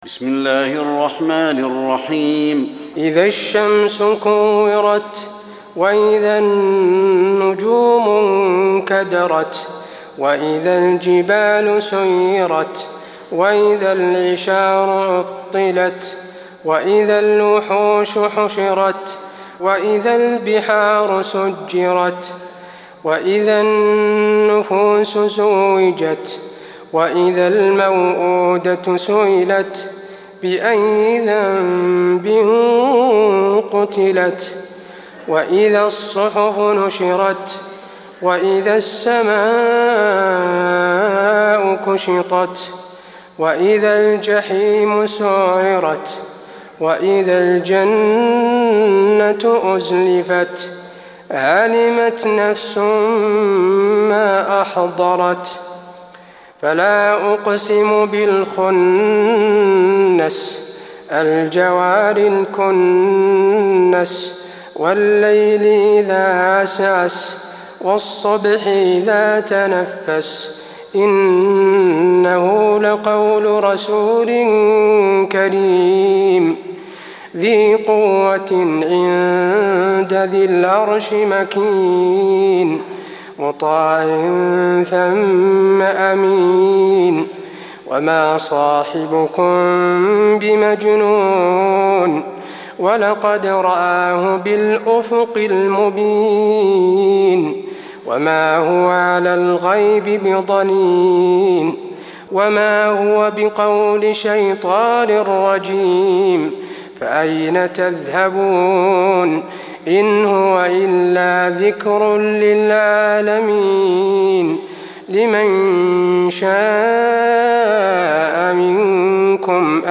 Sourate At Takwir du cheikh tarawih madinah 1423 en mp3, lire et telecharger sourate At Takwir